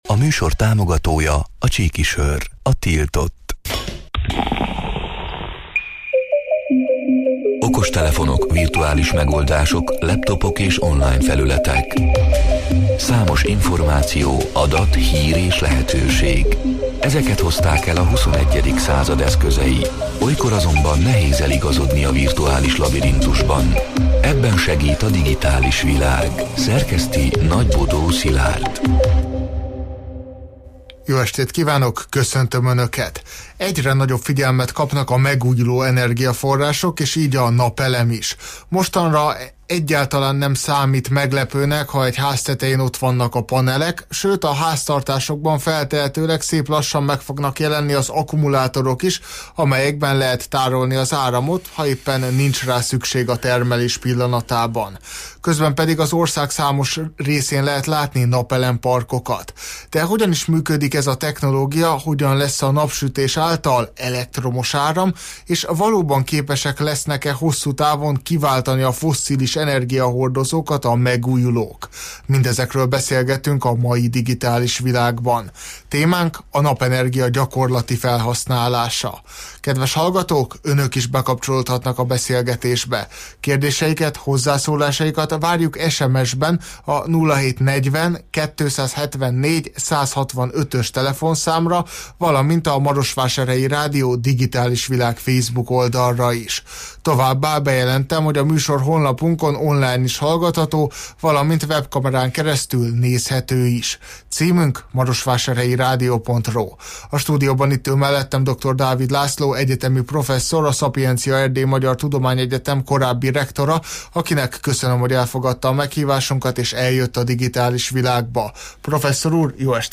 A Marosvásárhelyi Rádió Digitális Világ (elhangzott: 2025. szeptember 2-án, kedden este nyolc órától élőben) c. műsorának hanganyaga: